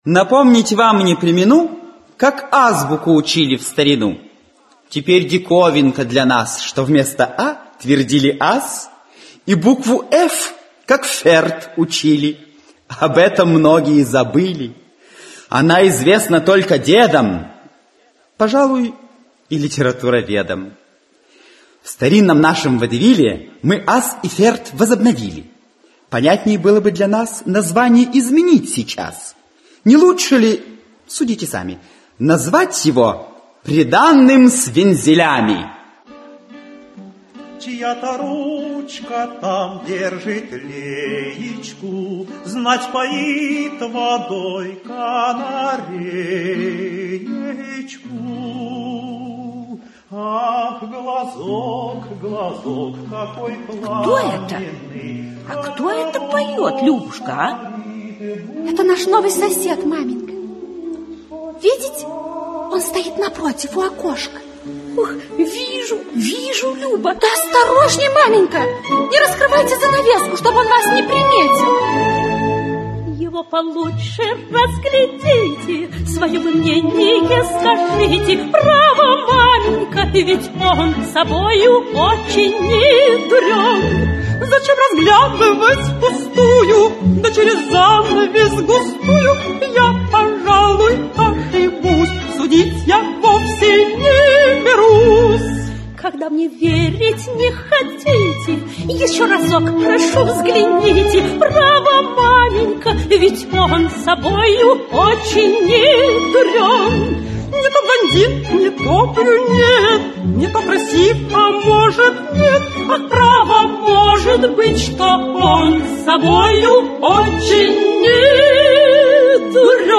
Аудиокнига Аз и Ферт, или Свадьба с вензелями (водевиль) | Библиотека аудиокниг
Aудиокнига Аз и Ферт, или Свадьба с вензелями (водевиль) Автор Павел Федоров Читает аудиокнигу Актерский коллектив.